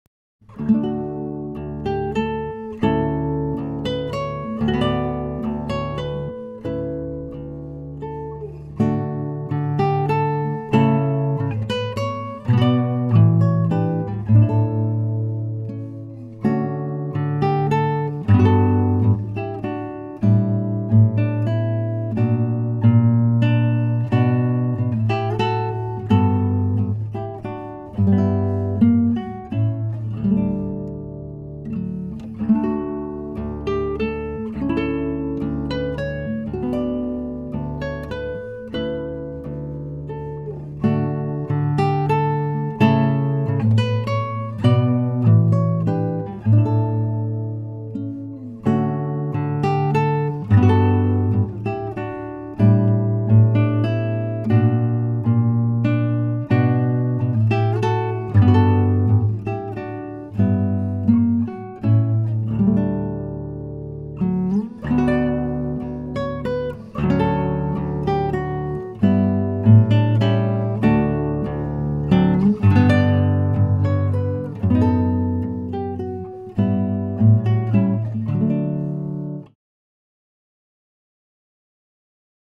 Gitarre
Gitarre - El neu de la mare.m4a